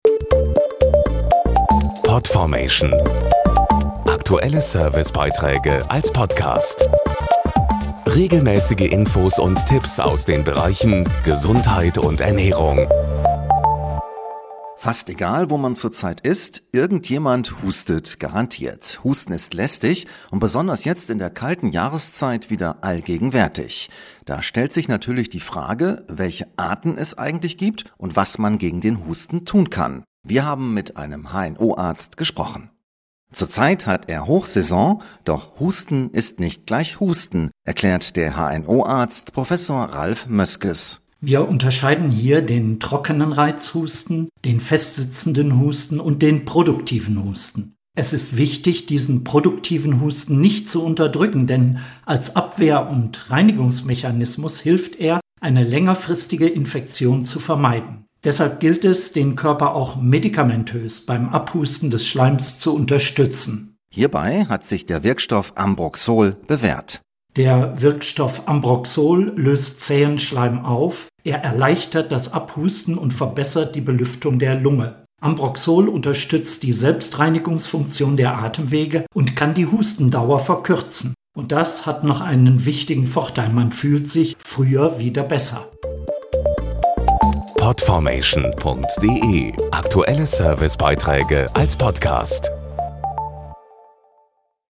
448_husten.mp3